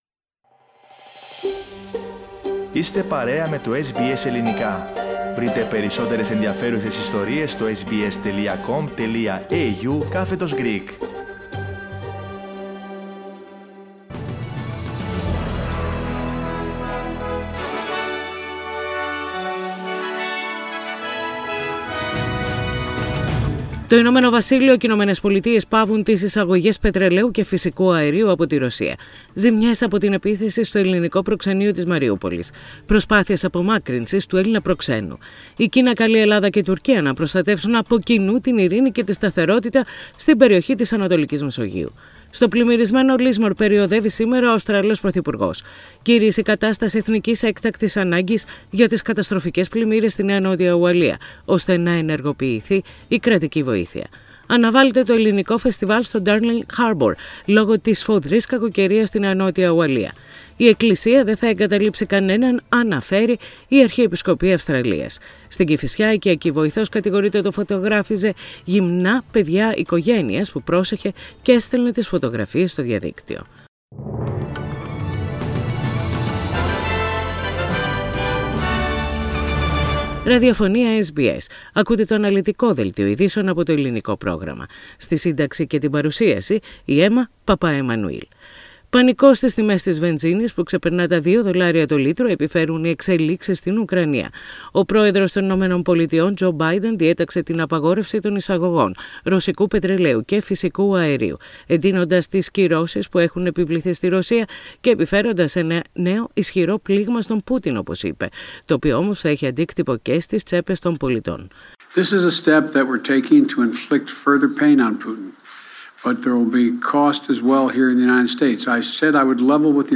Main news bulletin of the day.